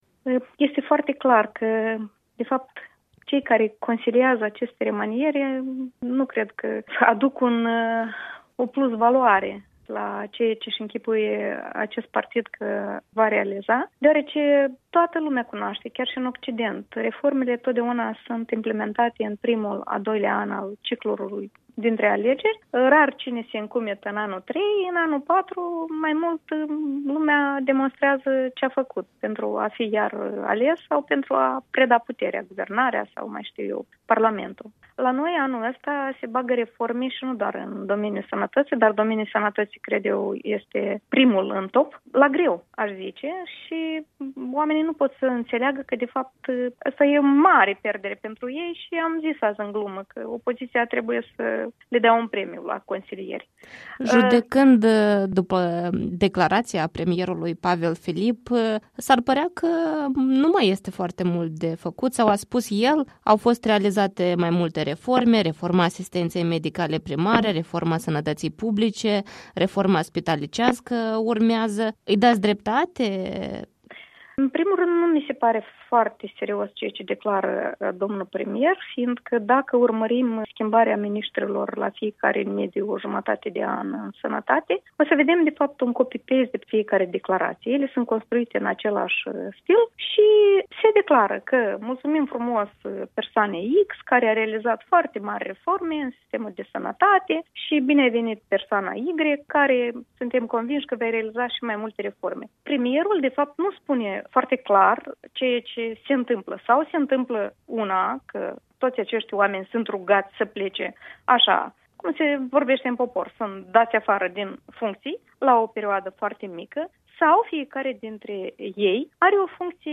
Interviul dimineții la EL: cu Ala Nemerenco